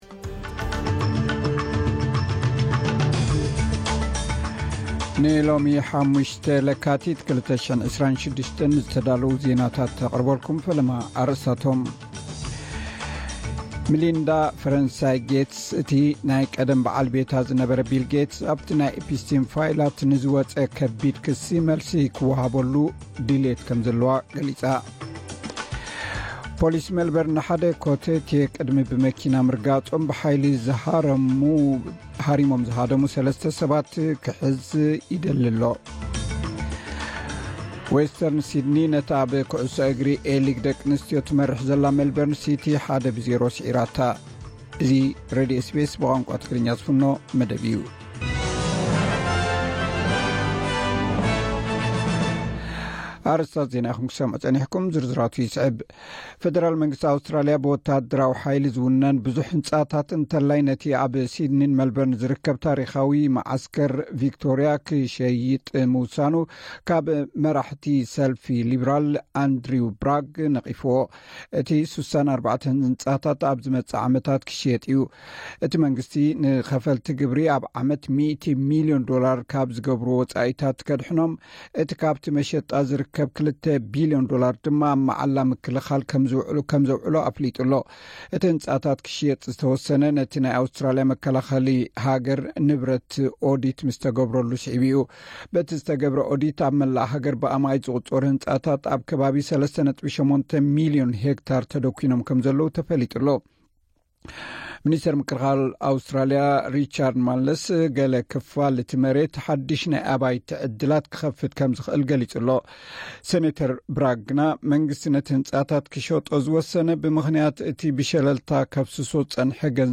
ዕለታዊ ዜና ኤስ ቢ ኤስ ትግርኛ (05 ለካቲት 2026)